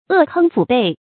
扼吭拊背 è gāng fǔ bèi 成语解释 扼：用力掐着；吭：咽喉；拊：拍击。